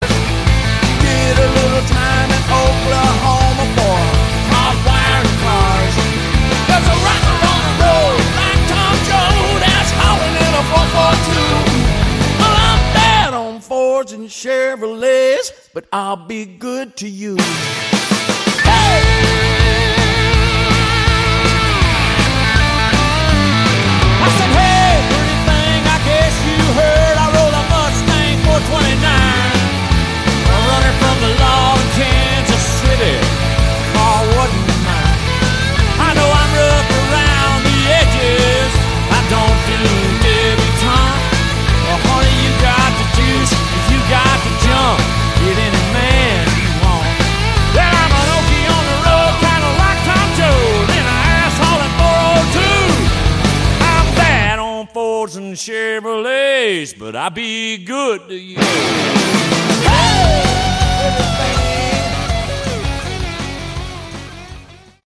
Category: Rock
lead vocals, guitar, acoustic guitar, lap steel